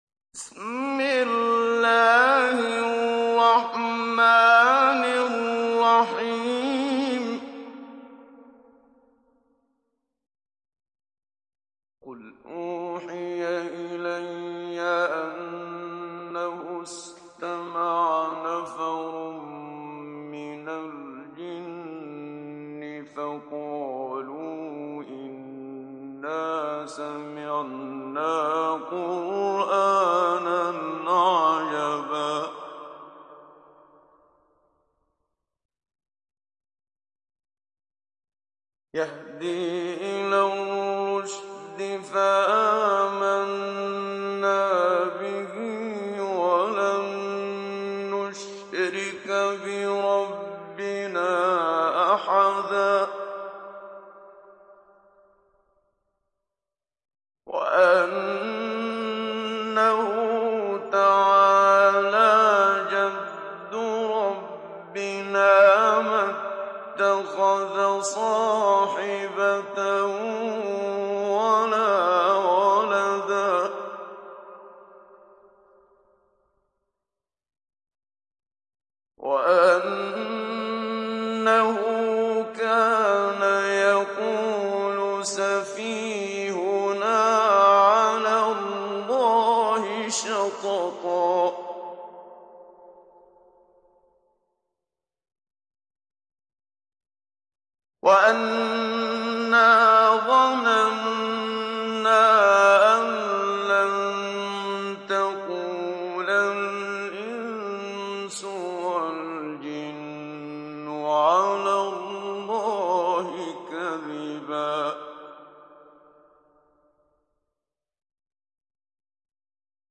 Télécharger Sourate Al Jinn Muhammad Siddiq Minshawi Mujawwad